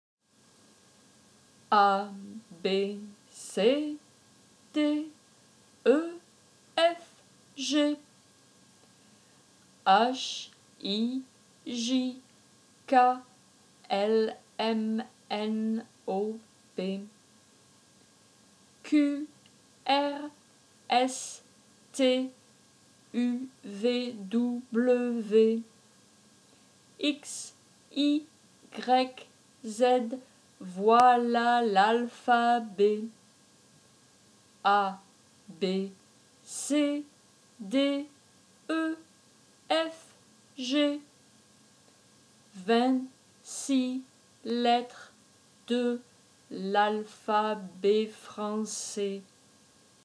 AlphabetFrançais_Slow.m4a